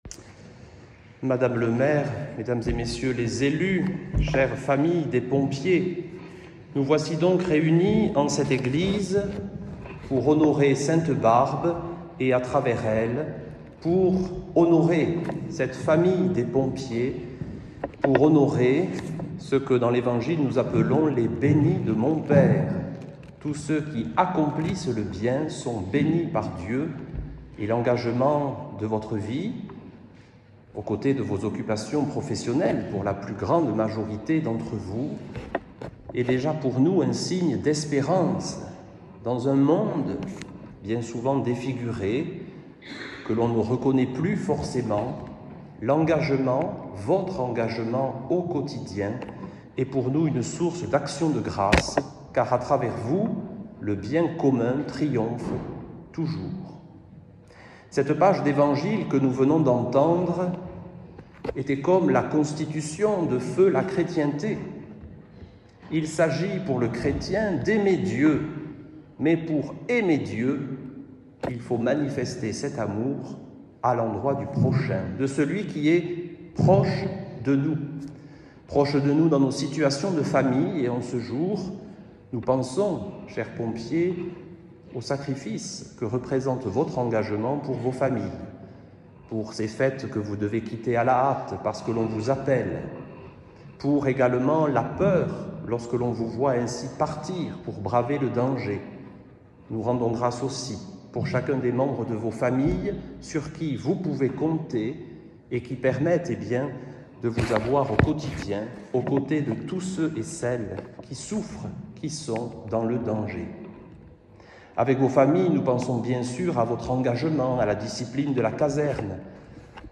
homelie-en-la-fete-de-sainte-barbe-patronne-des-pompiers-3.mp3